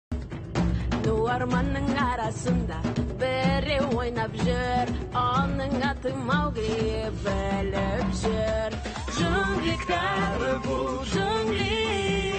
• Жанр: Русская музыка